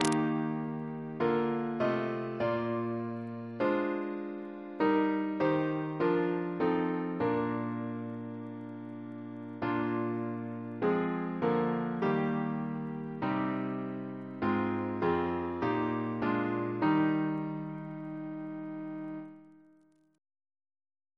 Double chant in E♭ Composer: William Hawes (1785-1846) Reference psalters: CWP: 58; OCB: 13; PP/SNCB: 11; RSCM: 68